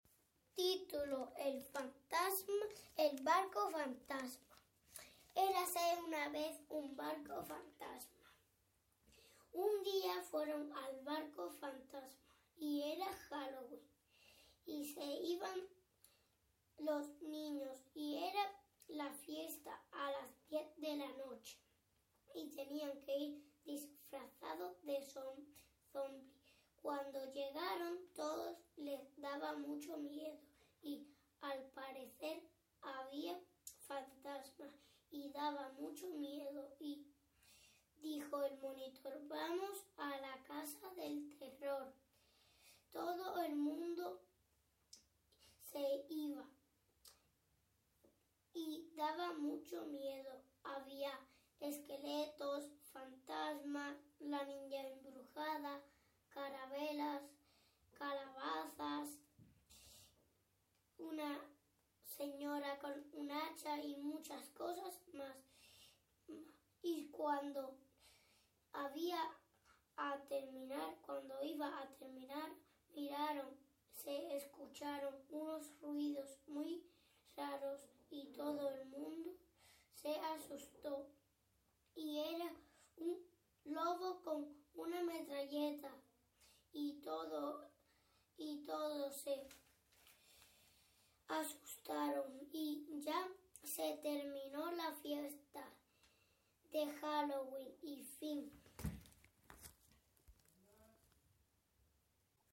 cuento